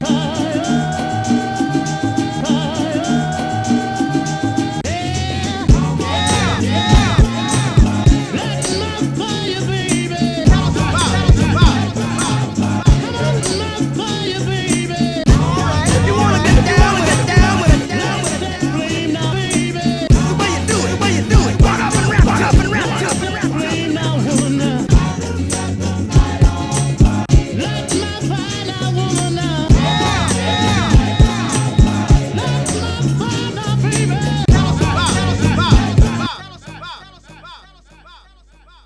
BPM は 100 っぽいが、もしかしたら間違ってるかも。
ローパスフィルターで低音を強調
ビート再現練習